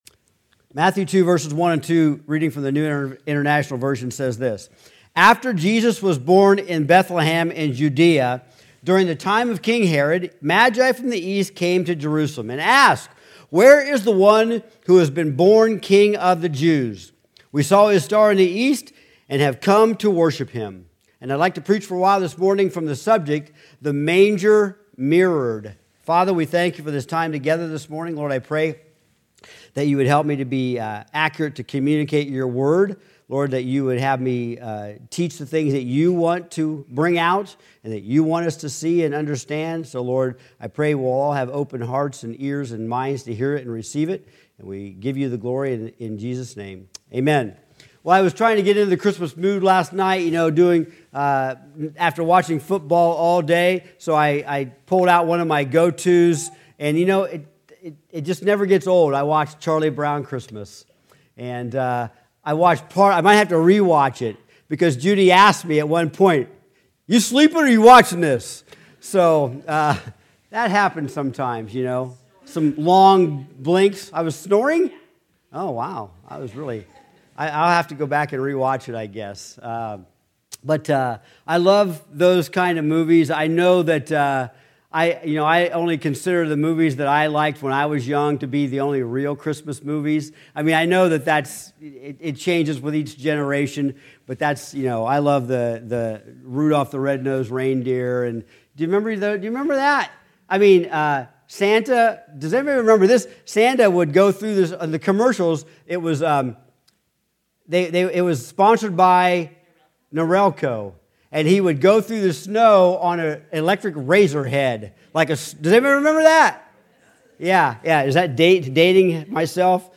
Sermons | Ohio City Community Church of God